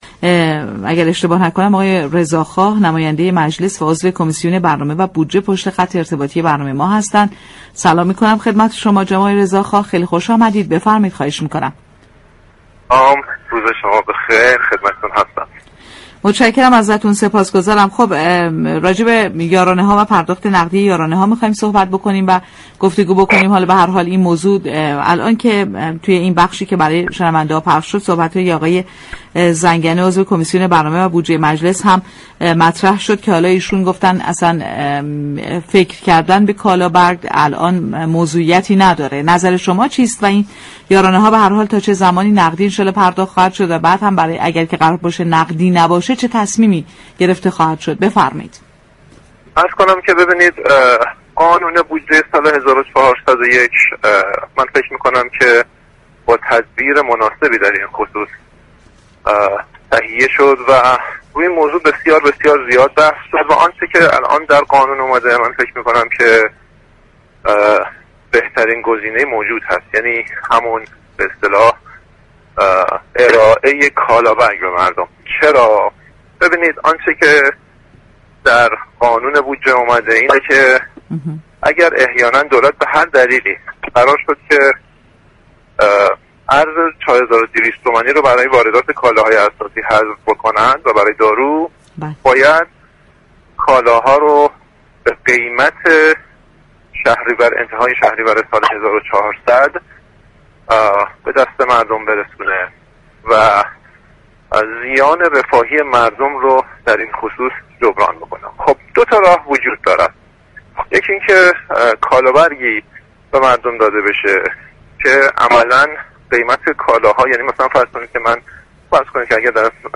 به گزارش پایگاه اطلاع رسانی رادیو تهران، مجتبی رضاخواه نماینده مردم تهران در مجلس در گفت‌و‌گو با بازار تهران رادیو تهران درخصوص نحوه پرداخت یارانه گفت: قانون بودجه سال 1401 با تدبیر خیلی خوبی تهیه شده است و آنچه در قانون آمده ارائه كالا برگ به مردم بهترین گزینه موجود است.